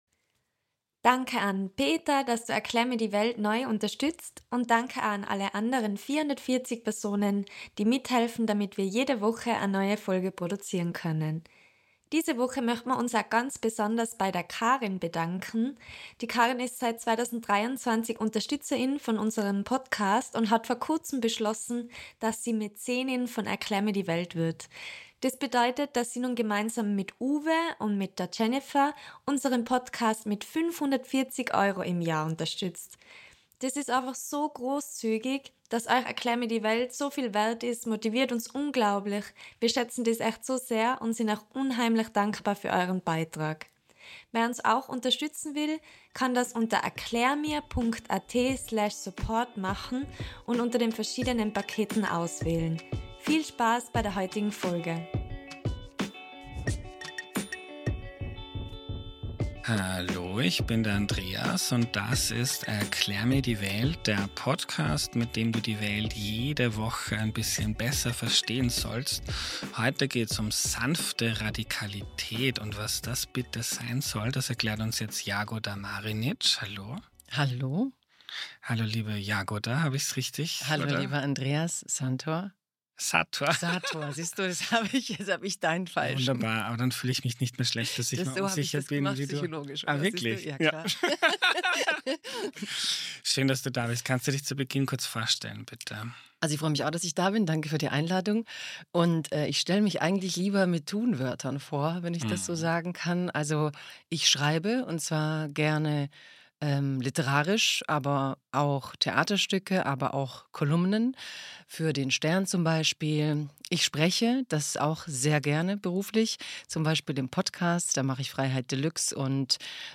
Darüber habe ich mit Jagoda Marinić gesprochen.